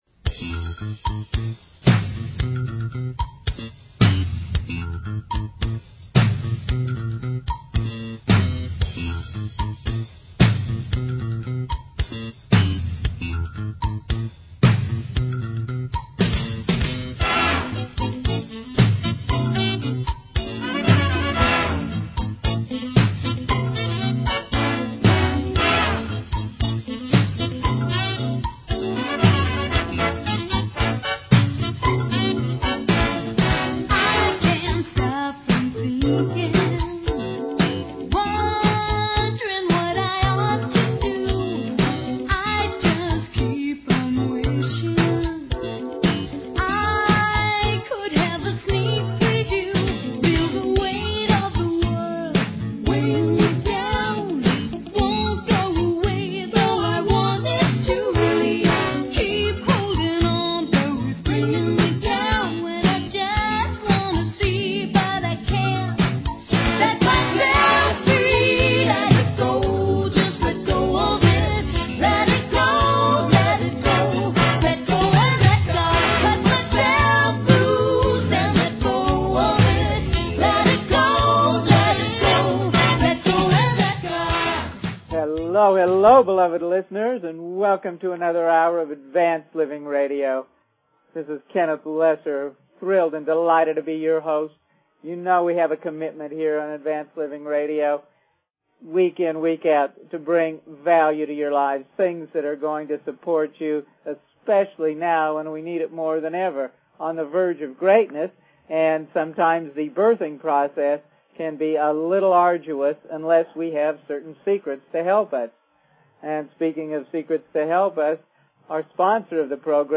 Talk Show Episode, Audio Podcast, Advanced_Living and Courtesy of BBS Radio on , show guests , about , categorized as